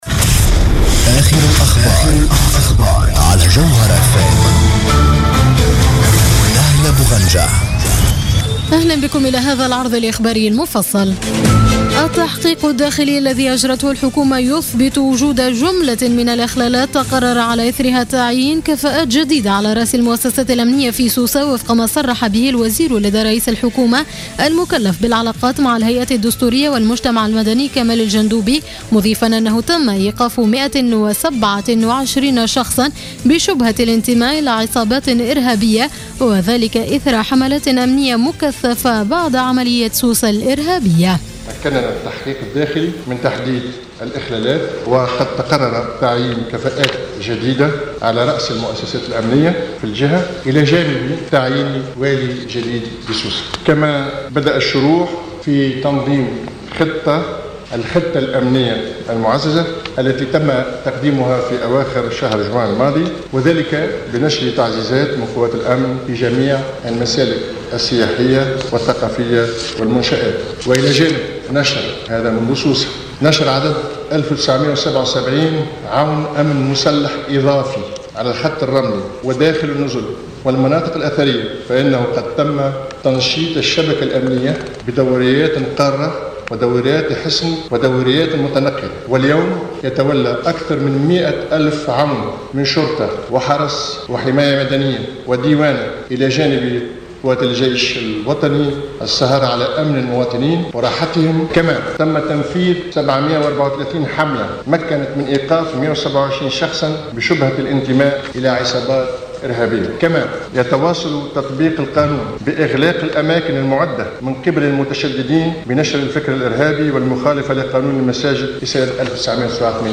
نشرة أخبار منتصف الليل ليوم السبت 11 جويلية 2015